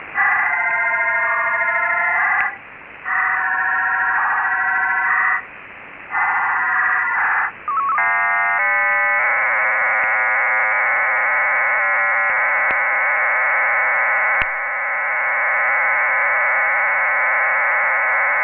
DIGTRX 8-TONE PSK EXPERIMENTAL HAM MODE All material Copyright © 1998 - 2008 No content on this website may be used or published without written permission of the author! DIGTRX at start of transmission DIGTRX at the end of a transmission back to PSK-systems page